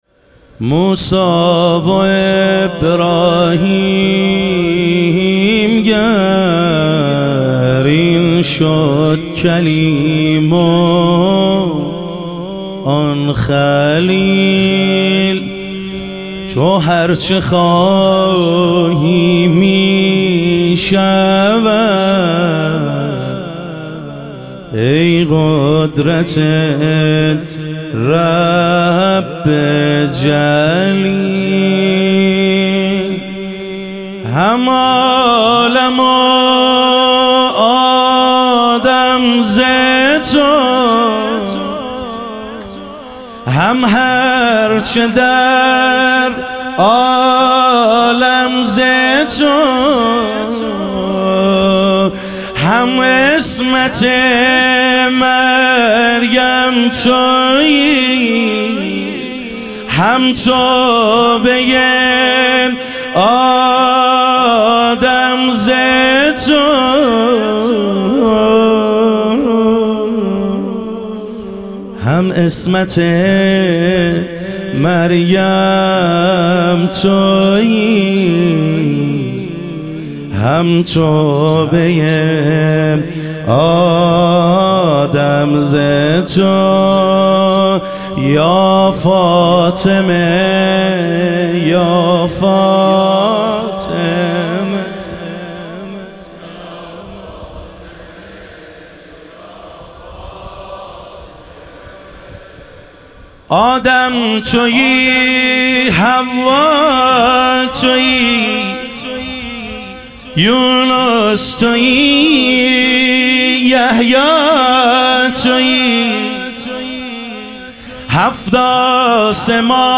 ظهور وجود مقدس حضرت زهرا علیها سلام - مدح و رجز